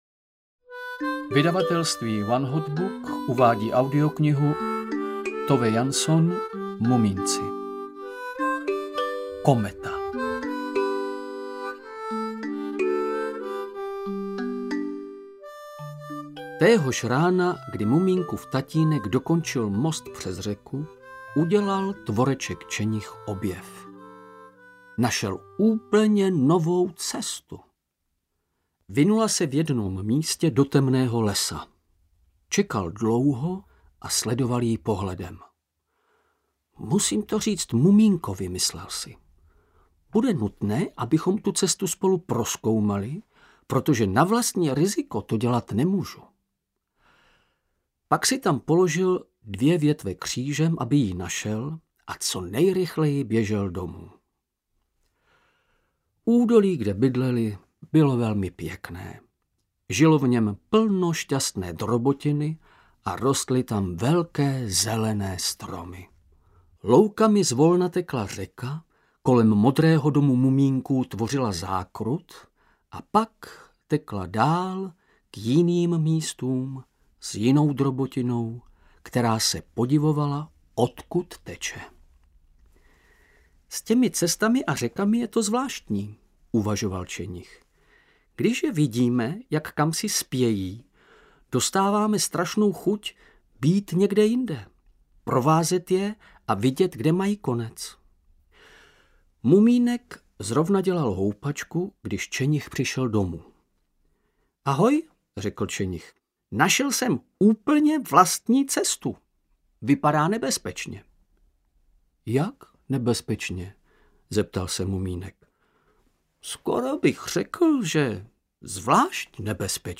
Mumínci a kometa audiokniha
Ukázka z knihy
• InterpretVladimír Javorský